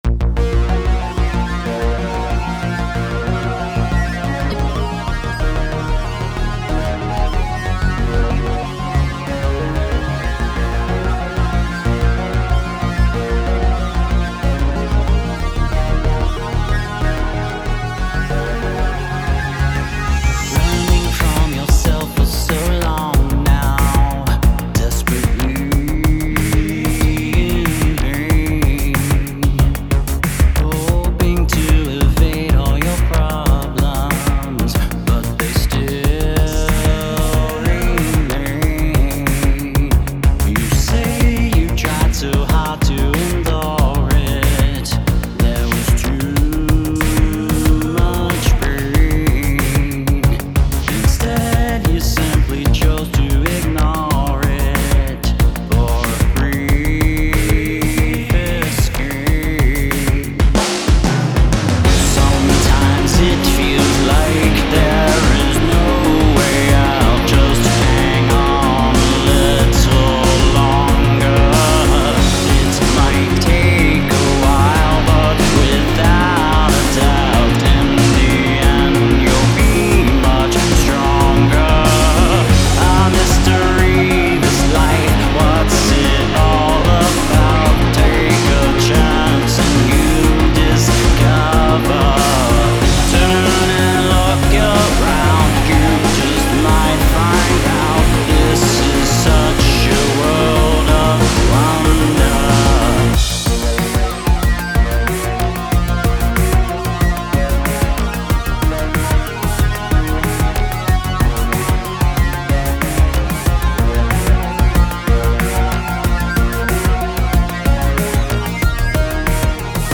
Industrial